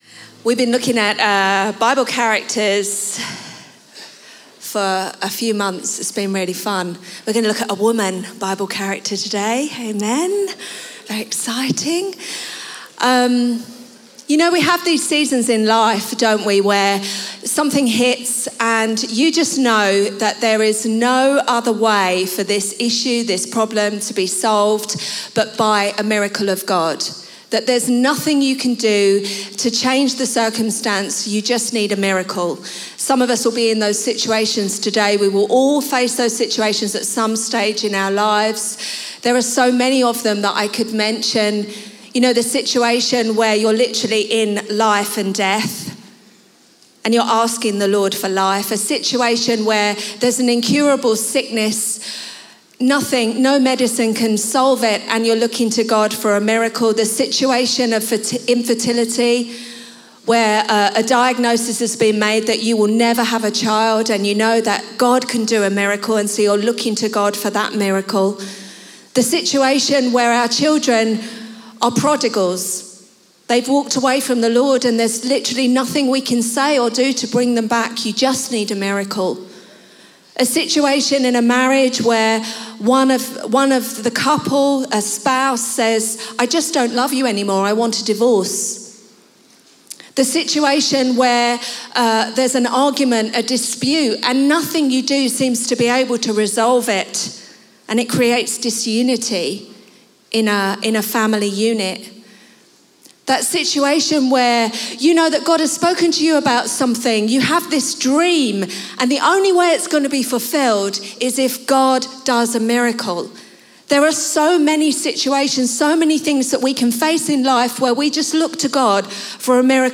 Chroma Church - Sunday Sermon Praying for a Miracle Apr 12 2023 | 00:26:34 1x 00:00 / 00:26:34 Subscribe Share RSS Feed Share Link Embed